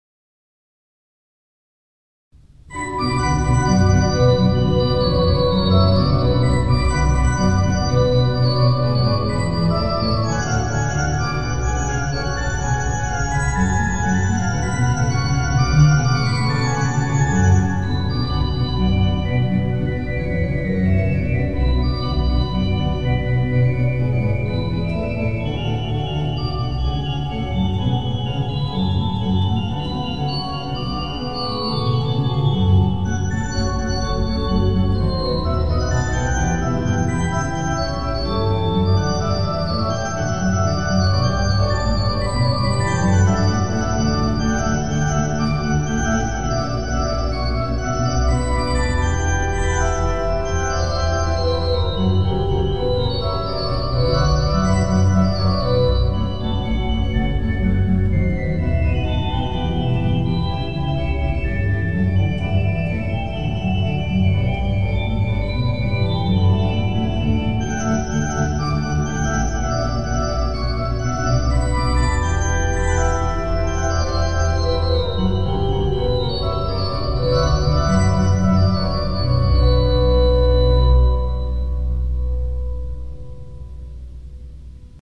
Orchestral Suite No 2 in B Minor BWV 1067  by JS Bach  —  1 minute 24 seconds